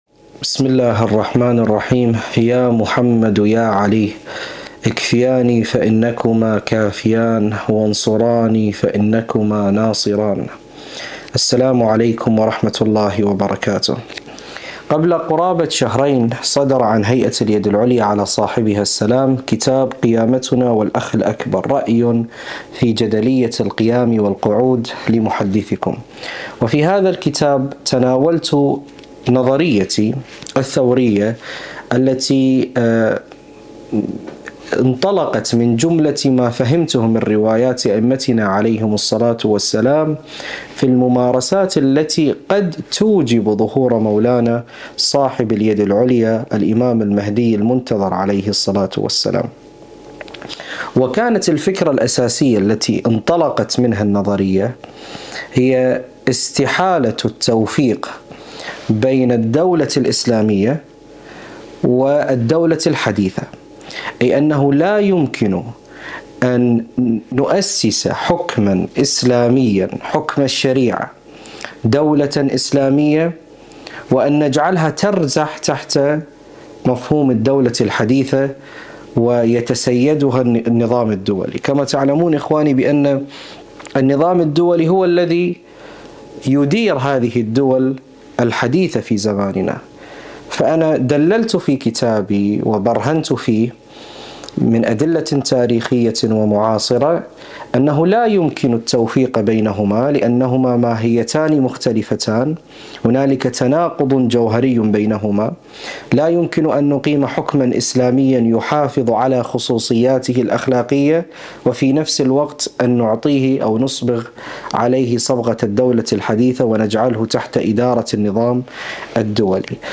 مكان التسجيل: لندن، المملكة المتحدة